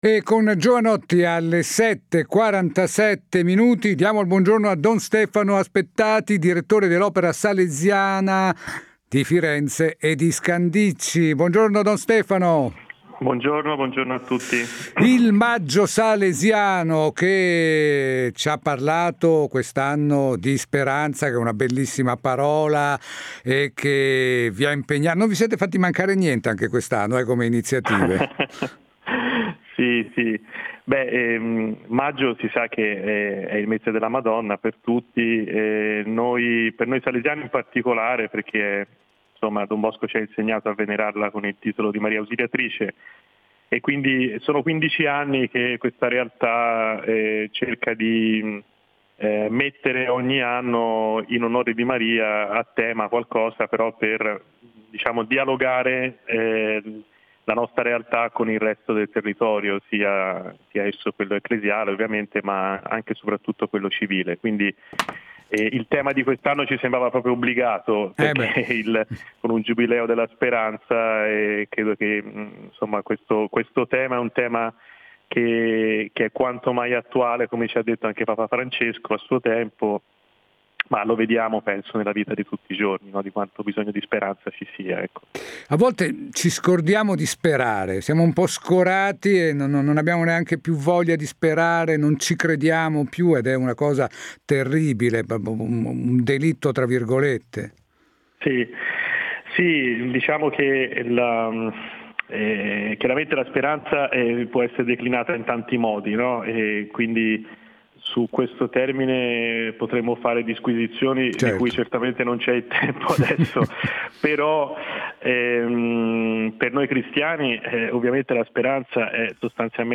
Intervista completa da Radio InBlu